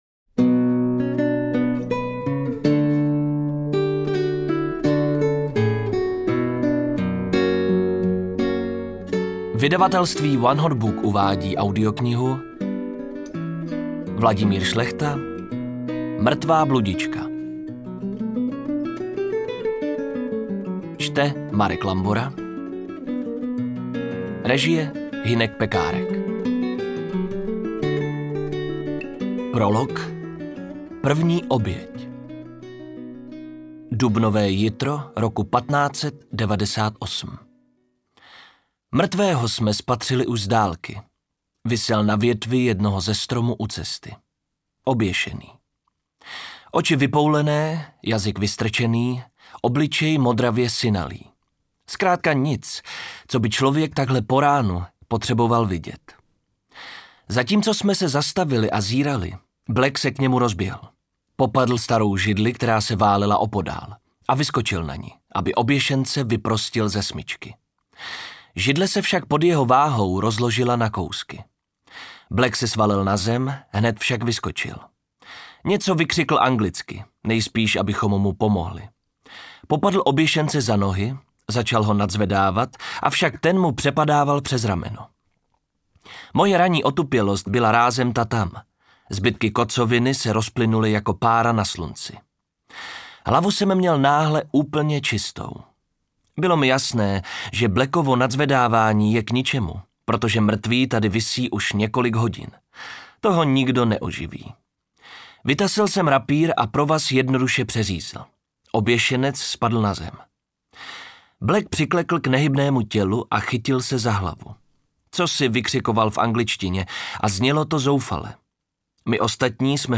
Interpret:  Marek Lambora
AudioKniha ke stažení, 26 x mp3, délka 8 hod. 30 min., velikost 459,0 MB, česky